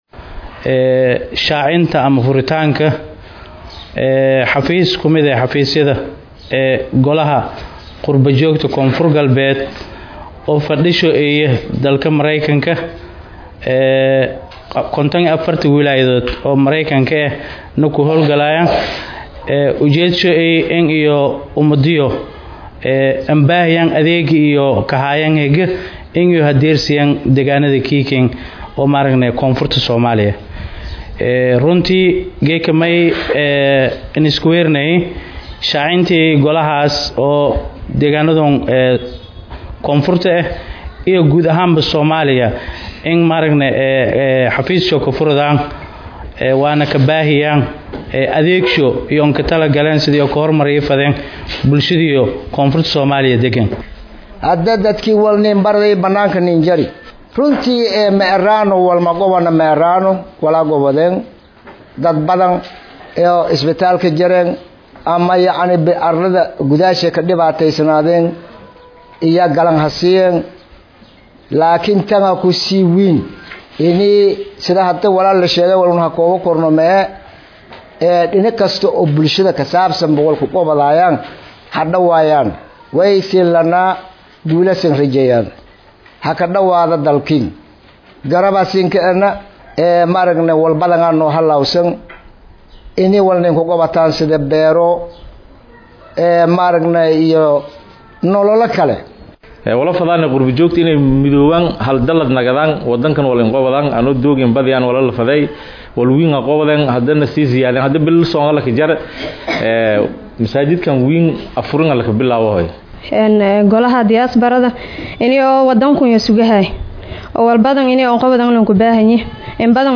Baydhabo(INO)-Kulankan lagu qabtey Magaalada Baydhabo waxaa ka qeyb galey aqoonyahano, odoyaasha dhaqanka,culmaa’udiinka,haweenka iyo dhalinyarada ayaa waxaa lagu shaacinayay xafiis gollaha qurba joogta koonfur Galbeed Soomaaliya ay ku yeeshen Baydhabo.